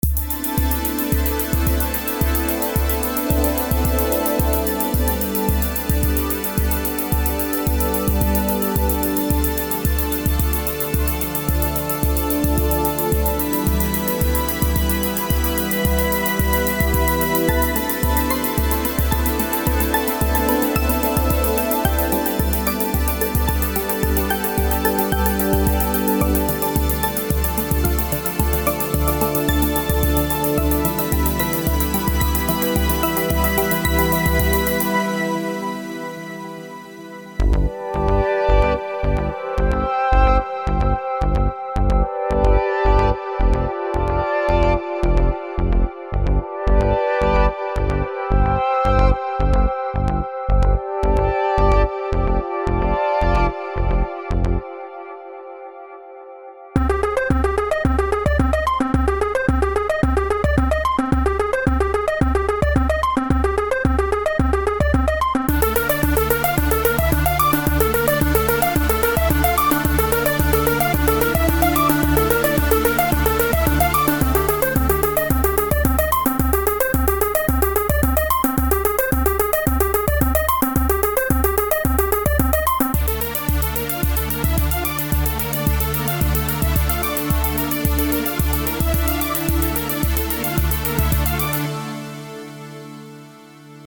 Sound categories: 19 Arps, 9 Atmospheres, 25 Basses, 10 Drums, 2 Hoovers, 11 Keys, 21 Leads, 27 Pads, 4 Percussive
Genres: Ambient, Chill Out, IDM, Downbeat and Electronica